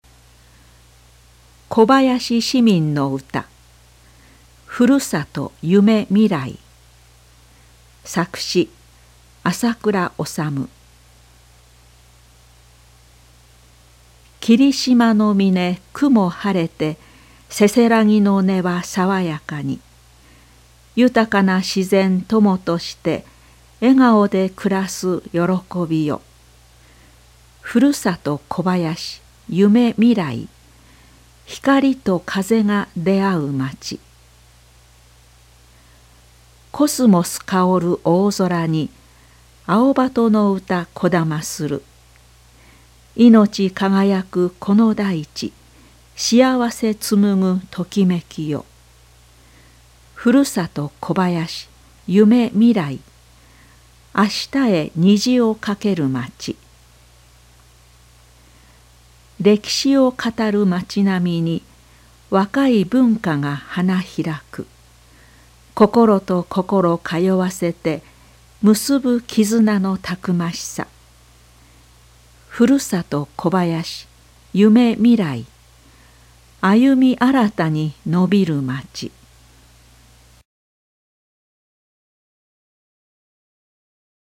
小林市民の歌～ふるさと ゆめ 未来～(朗読) (音声ファイル: 1.7MB)